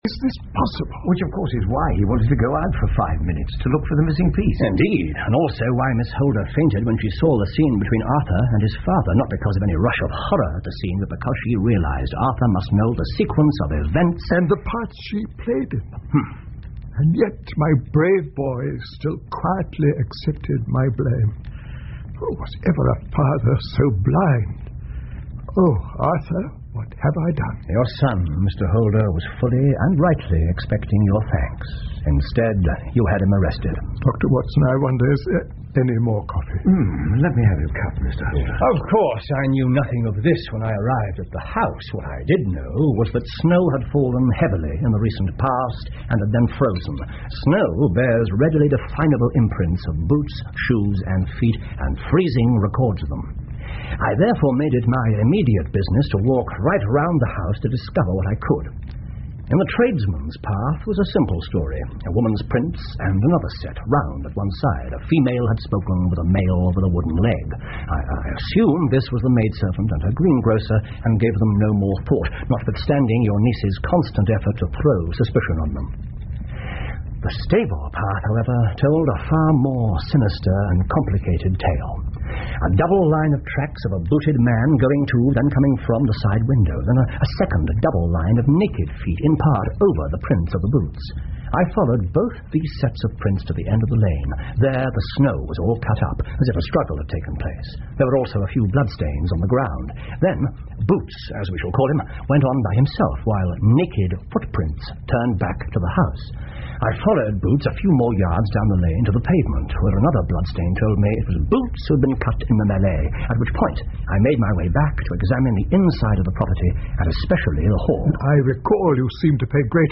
福尔摩斯广播剧 The Beryl Coronet 8 听力文件下载—在线英语听力室